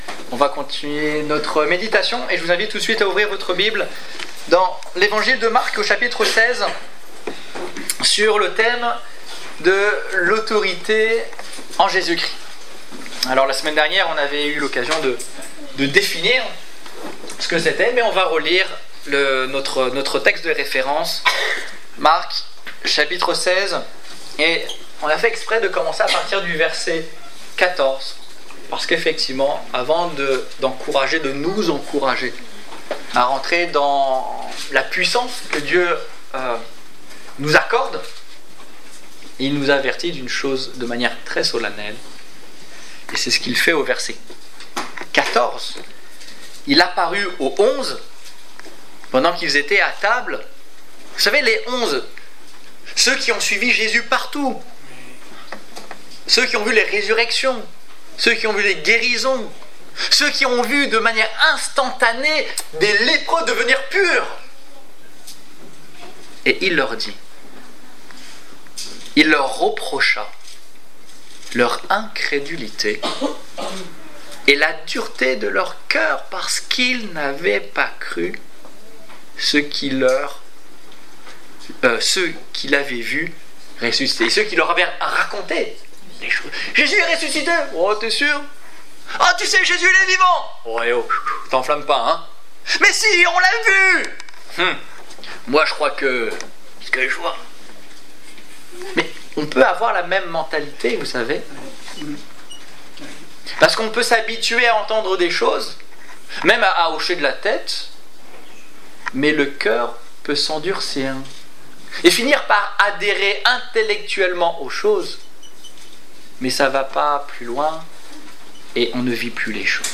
Autorité spirituelle (2) : Position spirituelle Détails Prédications - liste complète Culte du 12 juin 2016 Ecoutez l'enregistrement de ce message à l'aide du lecteur